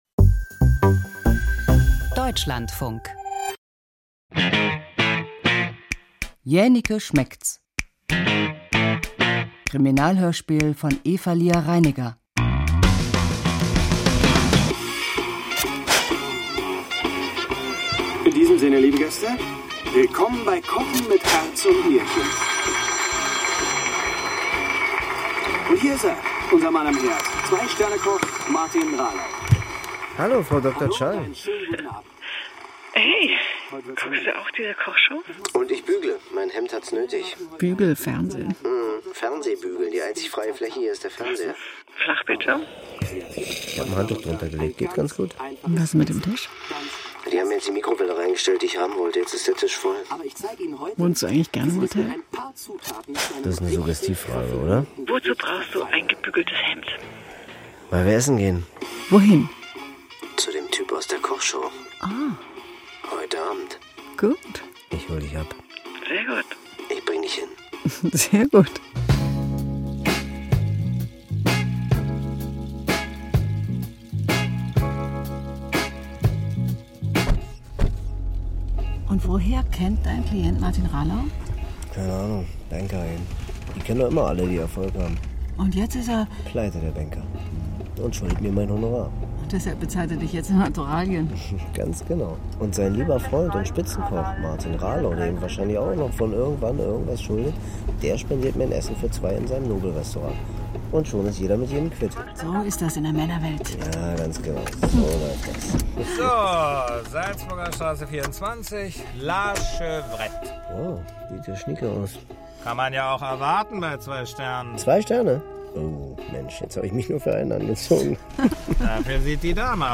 Jähnicke heuert undercover als Küchenhilfe im Sterne-Restaurant La Chevrette an und nimmt die Ermittlungen auf. Krimi-Hörspiel mit Milan Peschel.